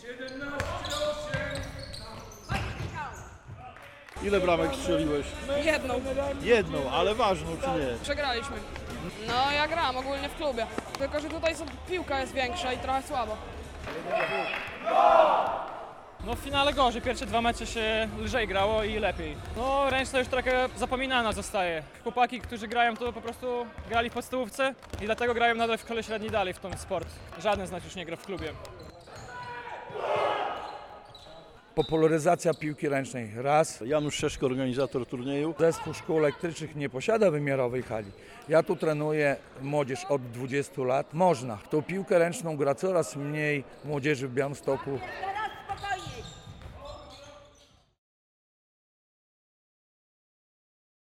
Pokazaliśmy młodzieży piłkę ręczną i to był nasz główny cel - mówią organizatorzy turnieju szkół ponadpodstawowych w szczypiorniaka.